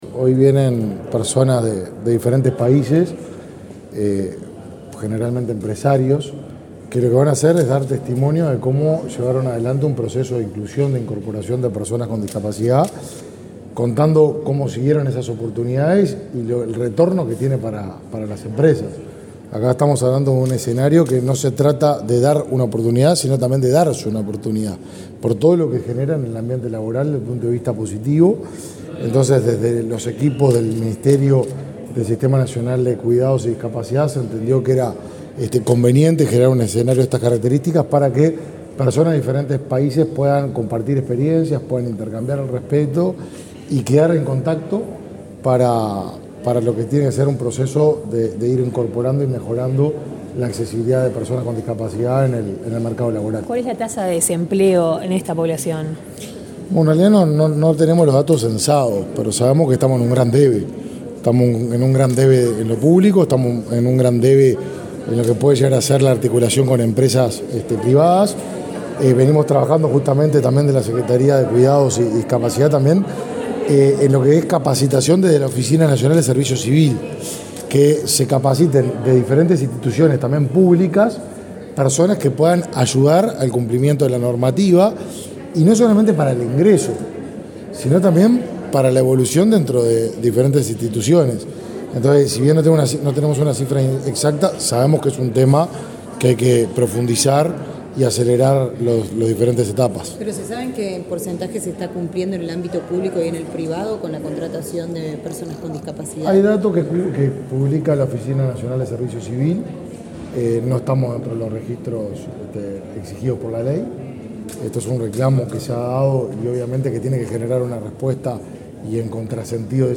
Declaraciones a la prensa del ministro de Desarrollo Social, Martín Lema
Luego dialogó con la prensa.